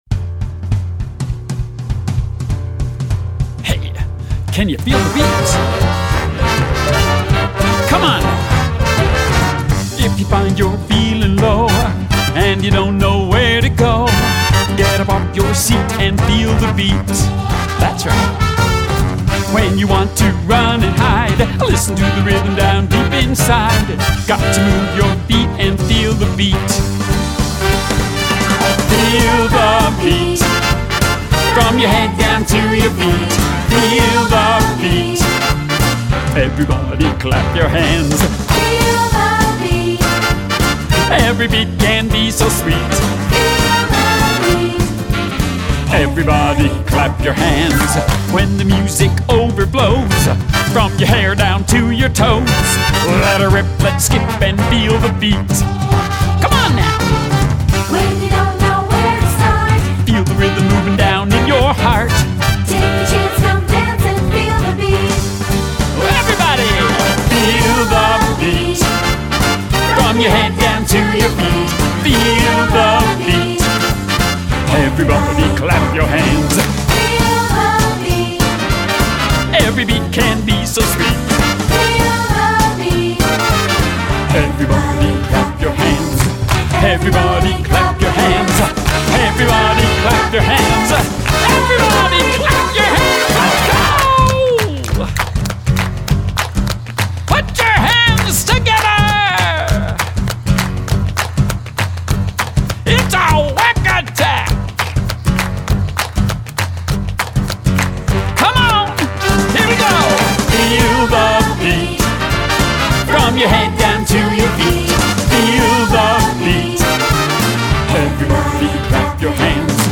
Voicing: Unison